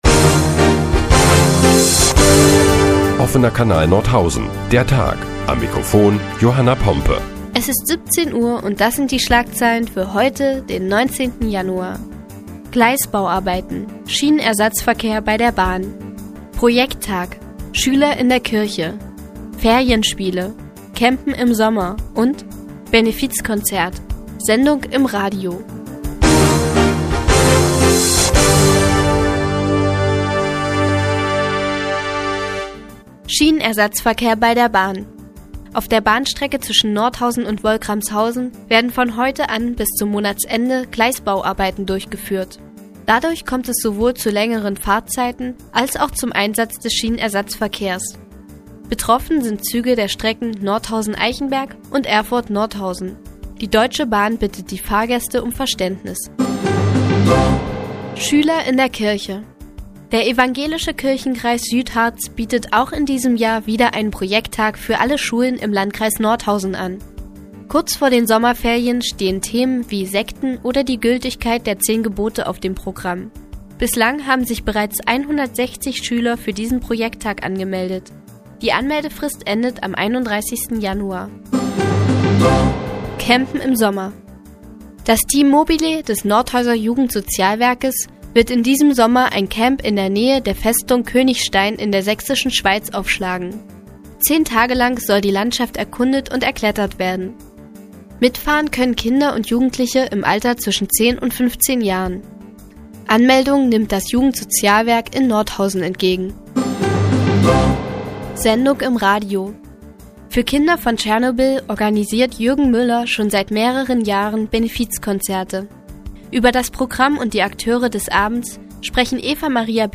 Die tägliche Nachrichtensendung des OKN ist nun auch in der nnz zu hören. Heute geht es unter anderem um Gleisbauarbeiten und Schüler in der Kirche.